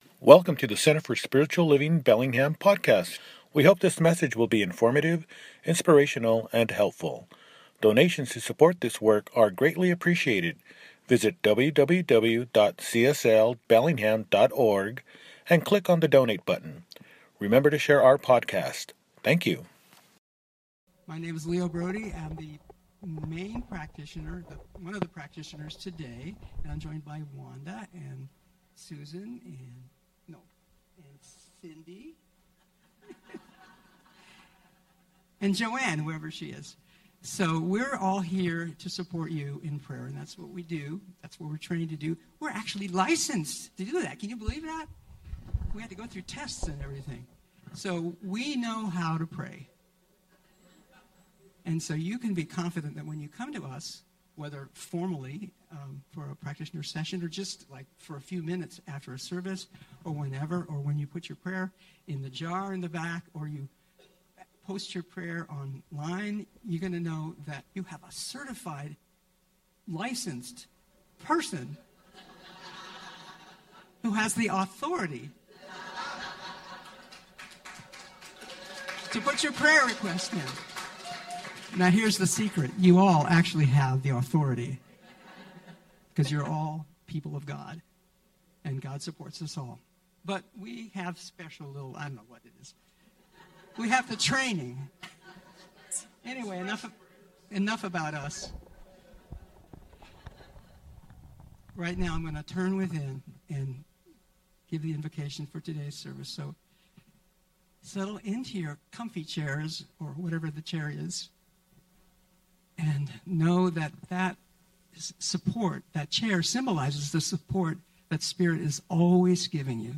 Nov 30, 2025 | Podcasts, Services